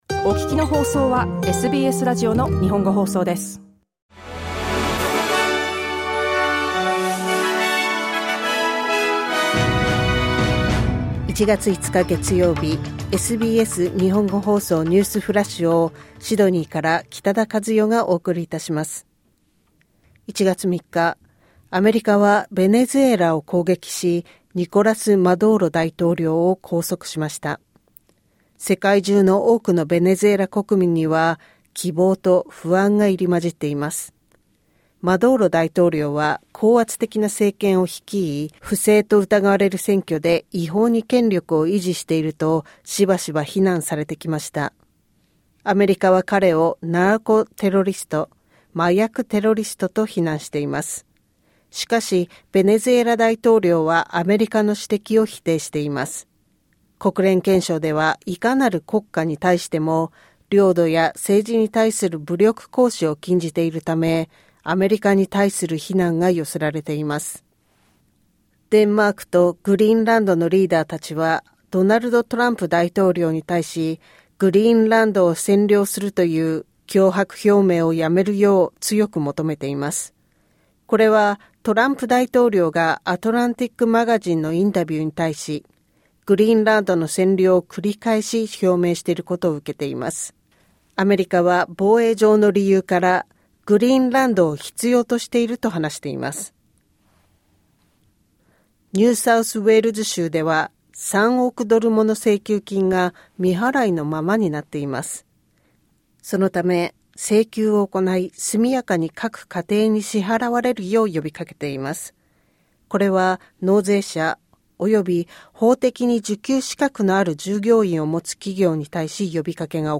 SBS日本語放送ニュースフラッシュ 1月5日 月曜日